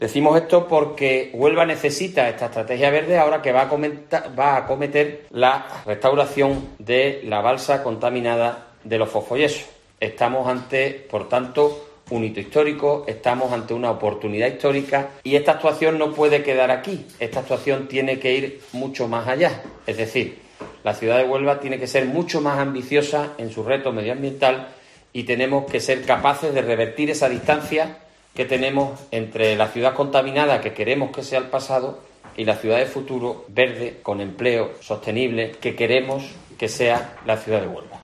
Jaime Pérez, portavoz del Grupo Popular en el Ayto de Huelva